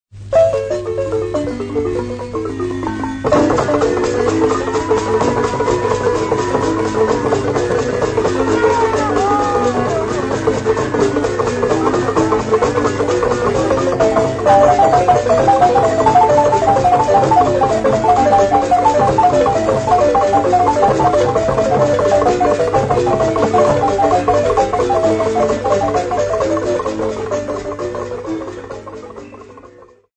TP4178-DXYZTL4630A.mp3 of Ngodo xylophone movement